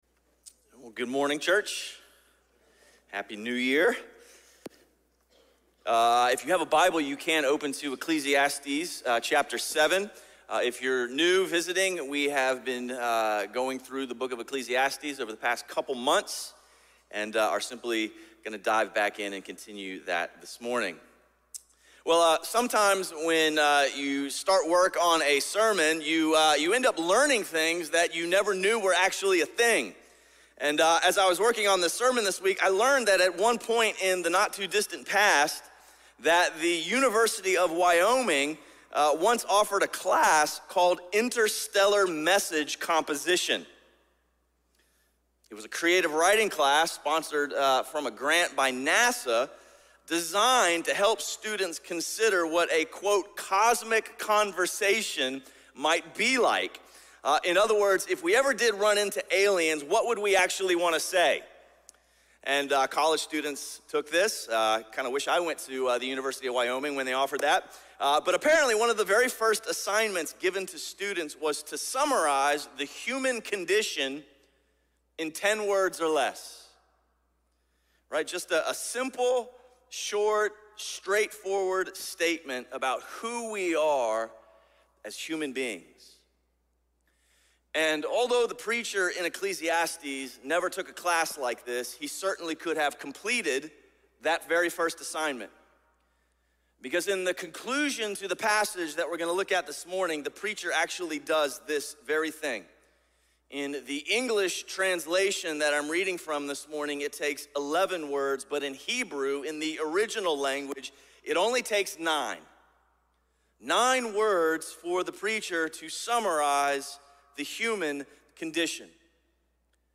A message from the series "Life Under the Sun."
Sermon series through the book of Ecclesiastes.